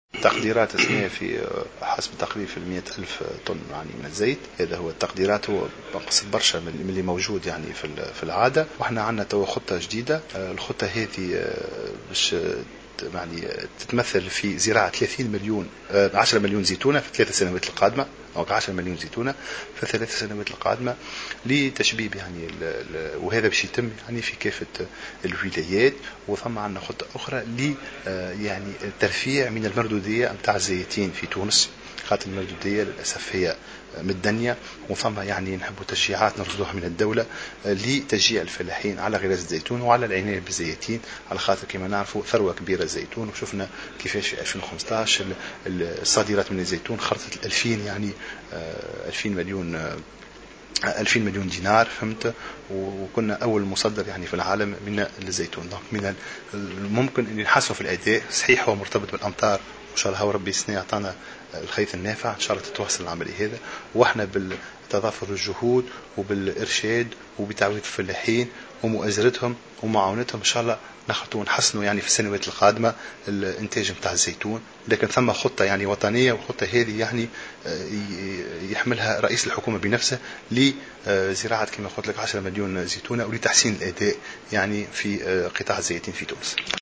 وأضاف في تصريح لمراسل "الجوهرة أف أم" على هامش زيارة ميدانية أداها اليوم لولاية المهدية أن صابة زيت الزيتون قد سجلت تراجعا مقارنة بالمواسم الفارطة.